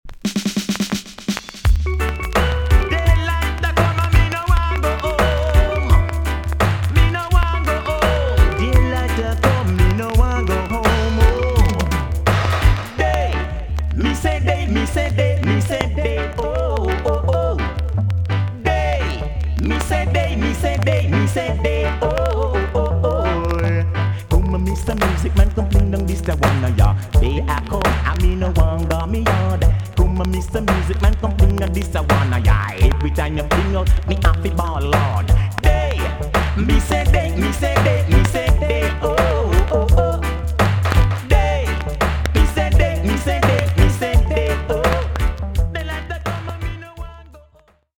TOP >80'S 90'S DANCEHALL
VG+ 少し軽いチリノイズが入ります。